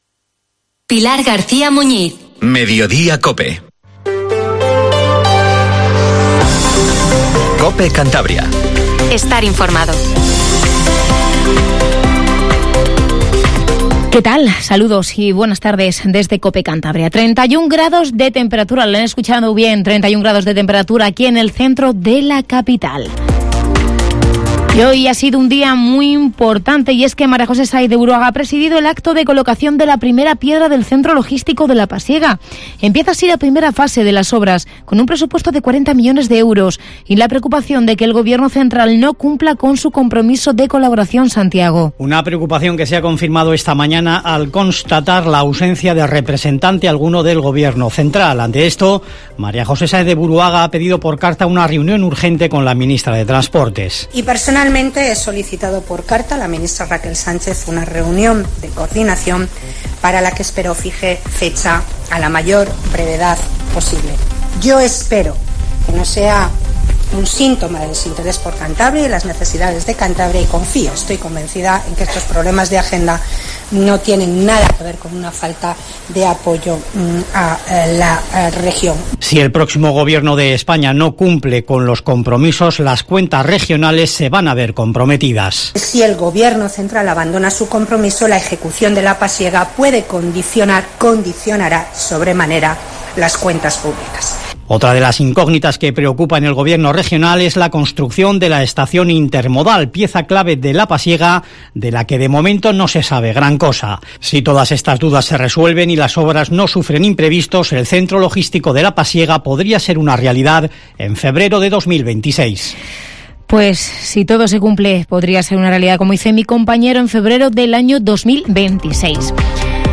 Informativo Mediodía COPE CANTABRIA 14:48